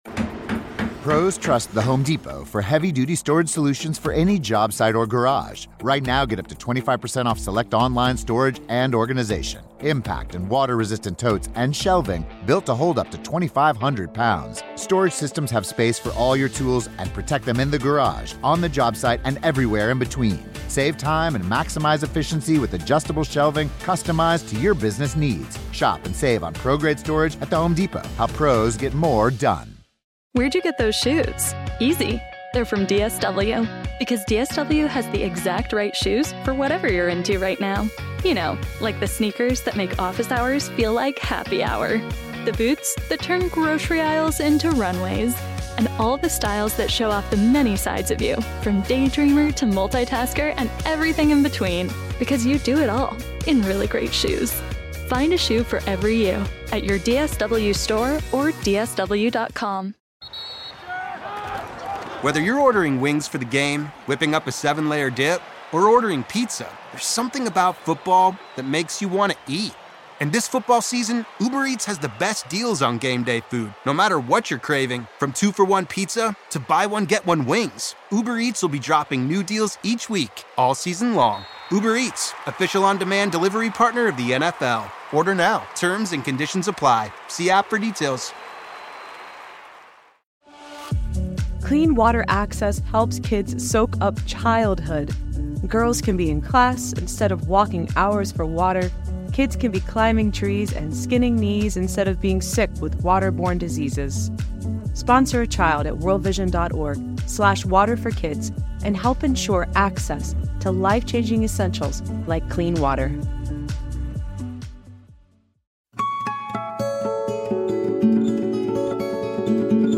We'll be playing his music throughout this story.